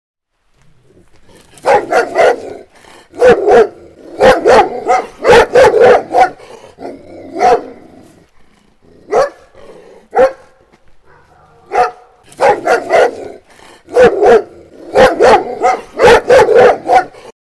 Security Dog Barking